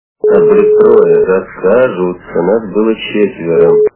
» Звуки » Из фильмов и телепередач » Дартаньян и 3 мушкетера - Нас будет трое, а скажут, что нас было четверо